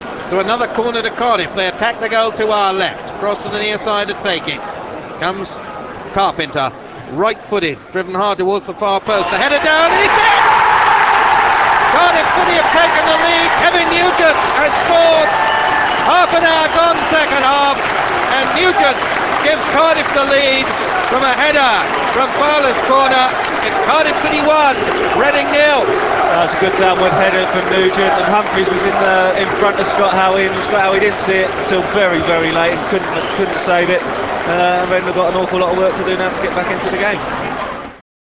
Audio: Supplied by Classic Gold radio.